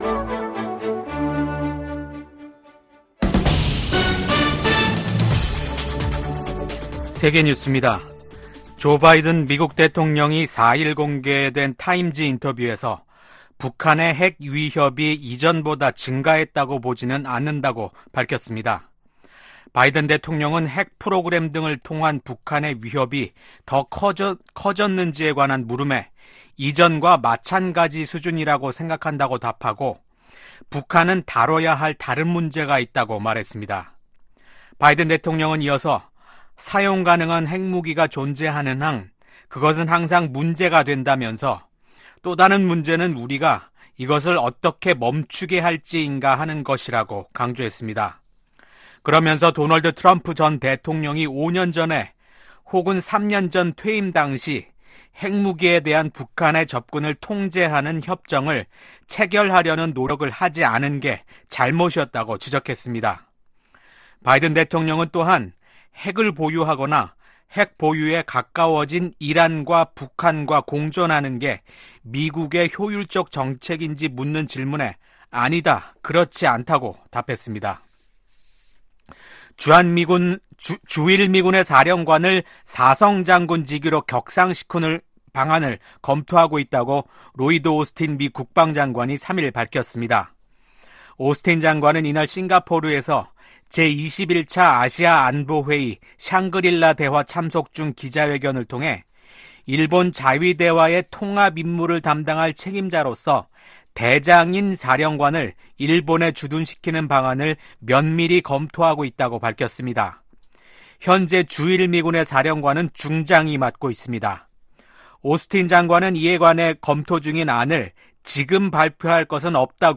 VOA 한국어 아침 뉴스 프로그램 '워싱턴 뉴스 광장' 2024년 6월 5일 방송입니다. 한국 정부는 9.19 군사합의 효력을 전면 정지시킴으로써 대북 확성기 사용과 함께 군사분계선(MDL)일대 군사훈련 재개가 가능하도록 했습니다. 미국의 북한 전문가들은 군사합의 효력 정지로 한국이 대비 태세를 강화할 수 있게 됐으나, 남북 간 군사적 충돌 위험성 또한 높아졌다고 진단했습니다.